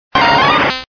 Cri de Boustiflor dans Pokémon Diamant et Perle.